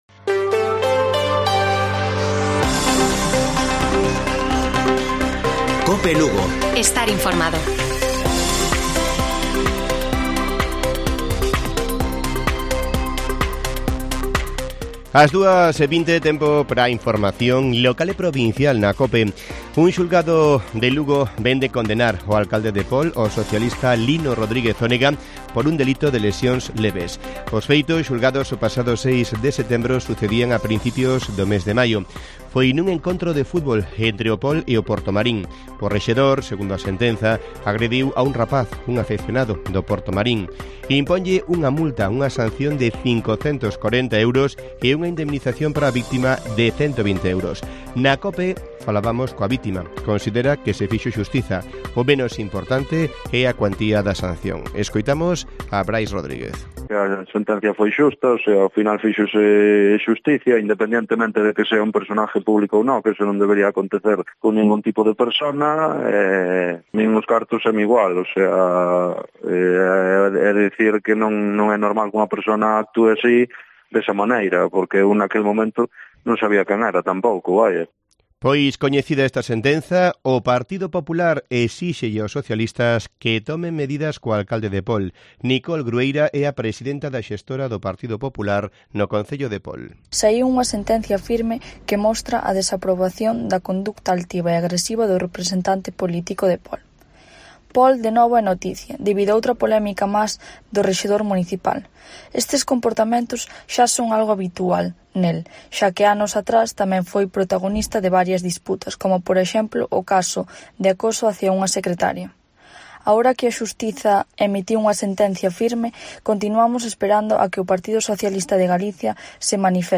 Informativo Mediodía de Cope Lugo. 14 DE OCTUBRE. 14:20 horas